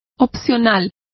Also find out how opcional is pronounced correctly.